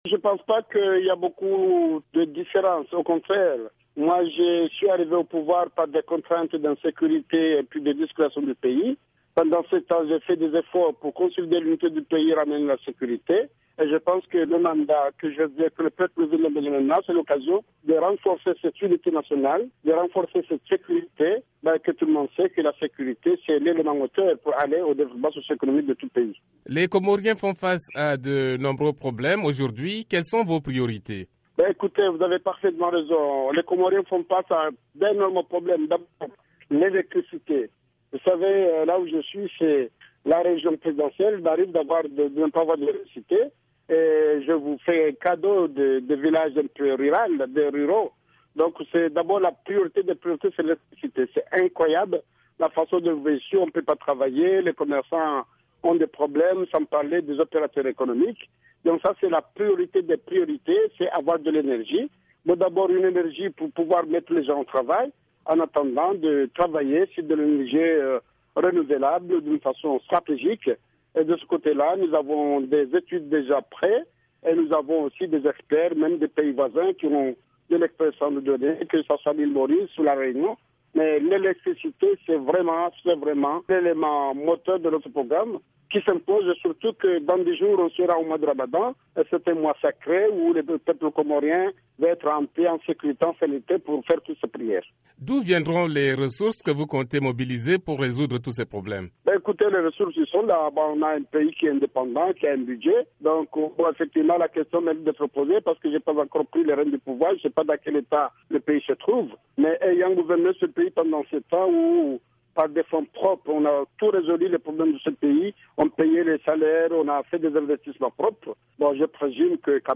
Un entretien avec le colonel Azali Assoumani, président élu des Comores